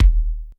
Kick_107.wav